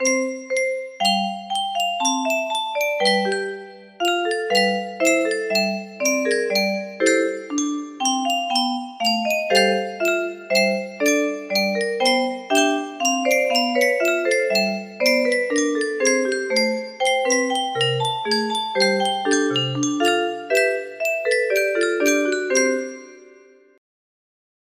Clone of Twinkle Twinkle Little Star (slower) music box melody
Yay! It looks like this melody can be played offline on a 30 note paper strip music box!